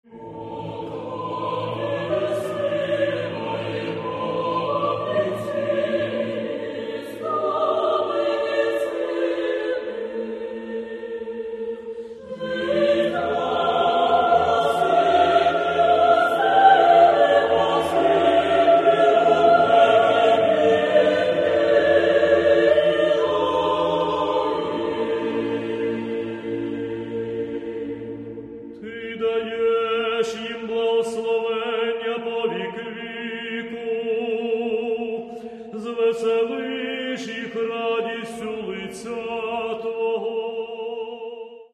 Каталог -> Классическая -> Хоровое искусство